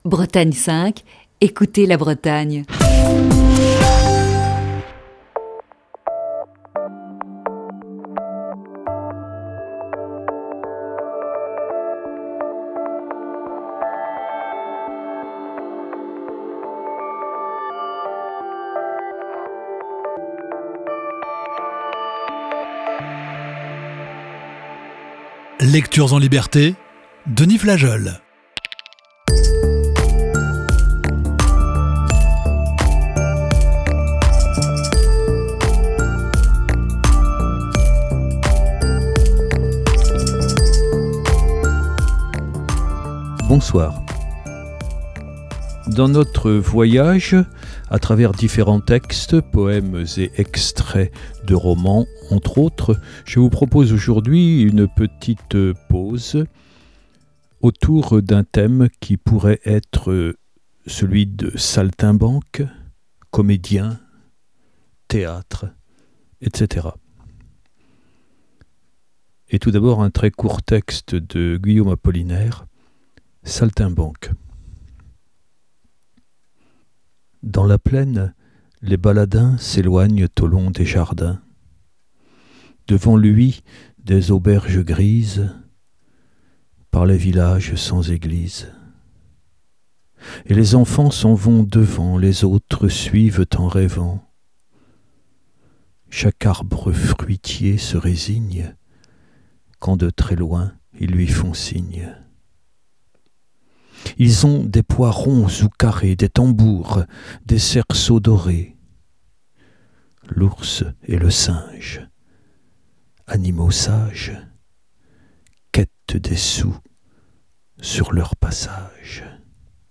la lectures de textes de divers auteurs